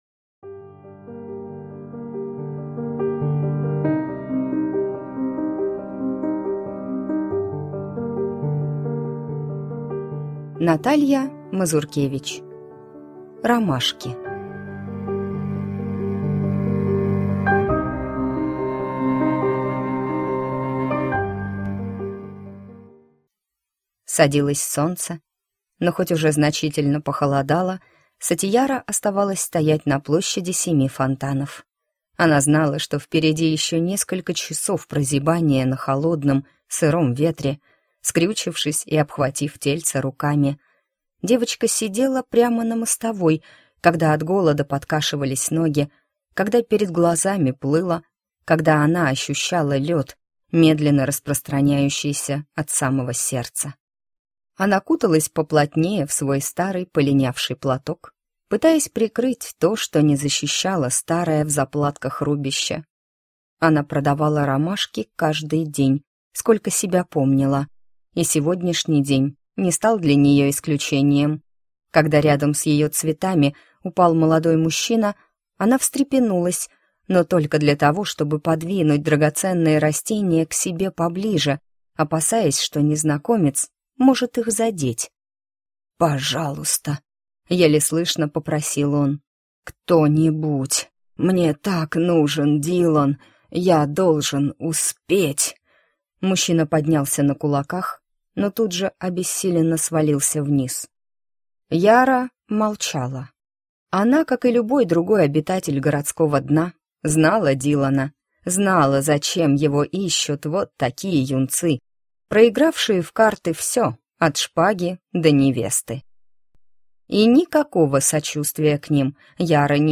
Аудиокнига Ромашки | Библиотека аудиокниг